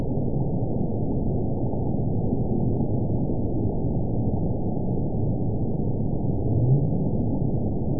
event 918213 date 08/14/23 time 17:37:52 GMT (1 year, 9 months ago) score 9.51 location TSS-AB02 detected by nrw target species NRW annotations +NRW Spectrogram: Frequency (kHz) vs. Time (s) audio not available .wav